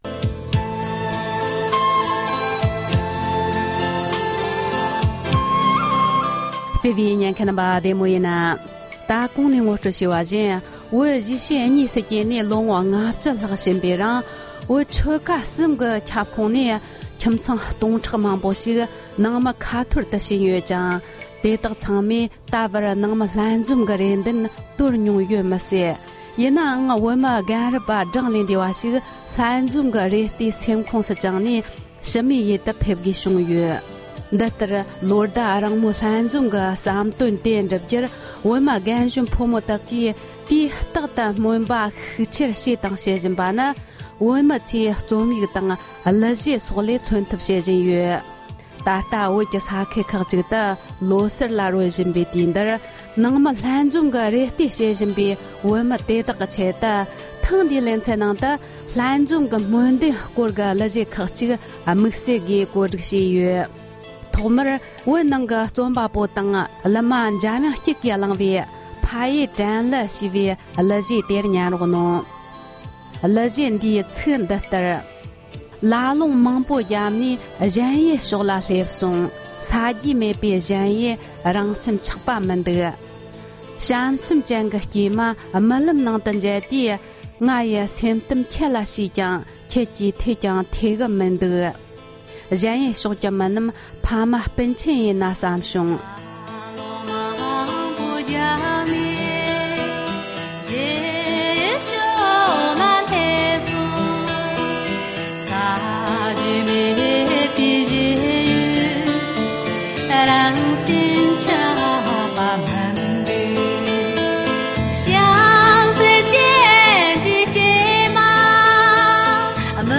དེ་དོན་མཚོན་པའི་གླུ་གཞས་ཁག་ཅིག